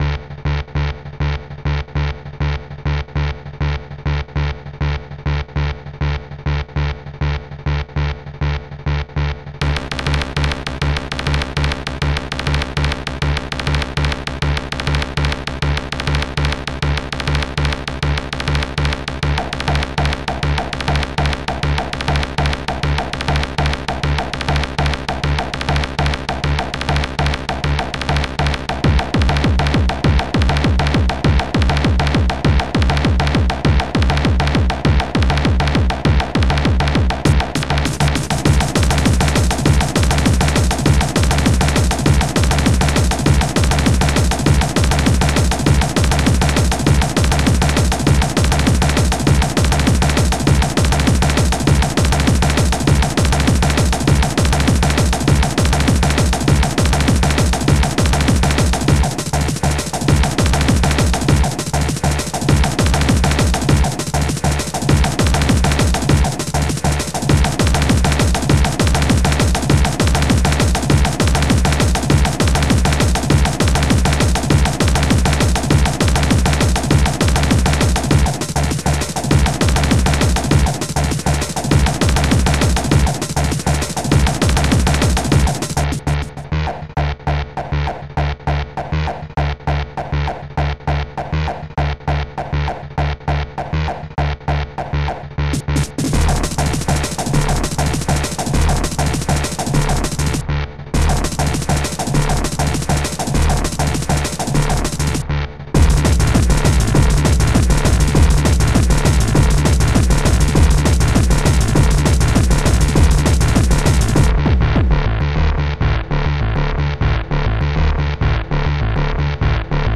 Protracker Module
yet another hardcore track !!!!